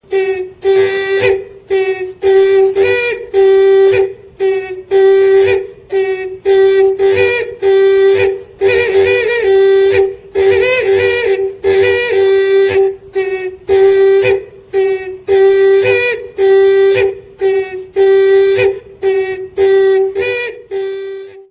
Clarinete heteróglota
Indígena Warao.
Aerófono, de soplo, de lengüeta.
El sonido se produce por el soplo que hace vibrar la lengüeta interna. Es un instrumento sagrado que se utiliza sólo en el baile de la ceremonia propiciatoria del Najanamu, luego se destruye.
Ensamble: Solo de Isimoi o pito grande
Característica: Toque propio de la ceremonia ritual denominada Najanamu
Procedencia, año: Barranquilla, Yuara Acojo, Caño Güiniquina, estado Delta Amacuro, Venezuela, 1978